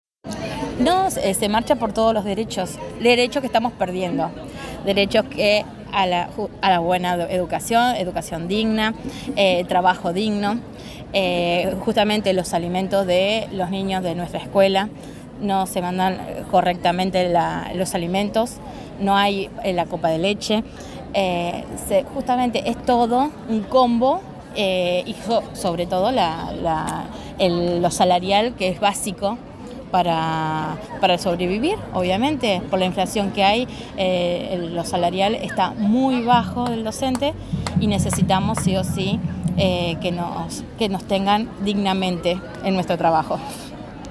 Escucha los relatos en primera persona de quienes asistieron!